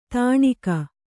♪ tāṇika